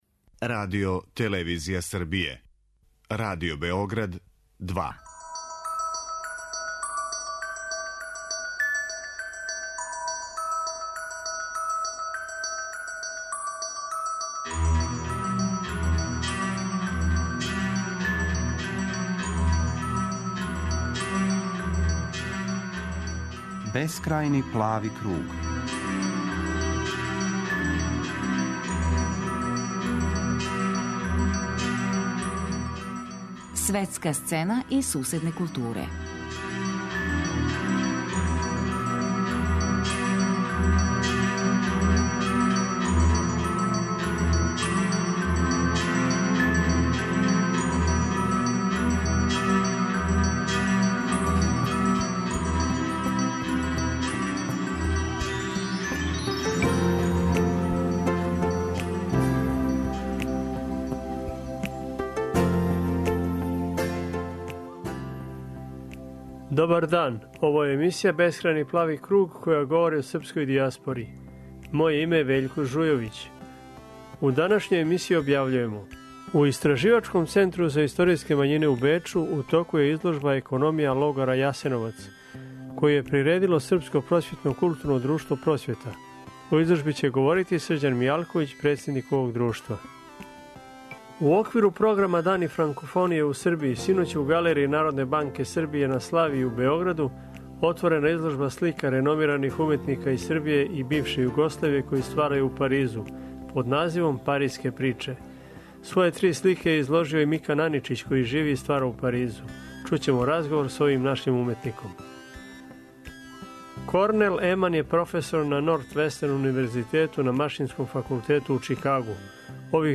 Чућемо разговор са овим нашим уметником.